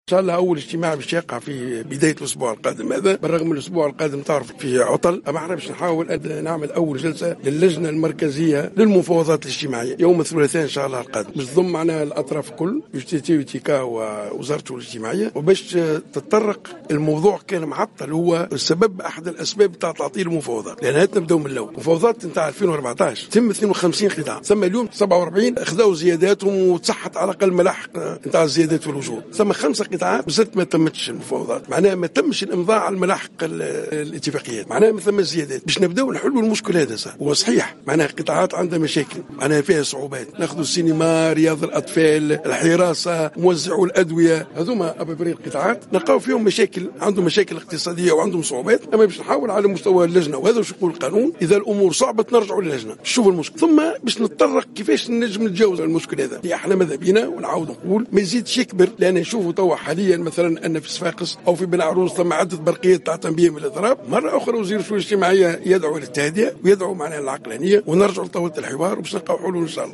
أعلن وزير الشؤون الاجتماعية أحمد عمار الينباعي في تصريح لمراسل الجوهرة أف أم
على هامش ندوة علمية